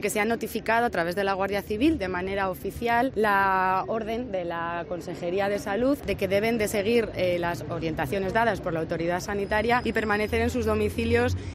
No ha habido cuartentena sino una "vigilancia activa" porque hay un número importante de personas aisladas en su domicilio y se está comprobando que cumplan con esta medida. Matía Marrodán es la Delegada del Gobierno en La Rioja: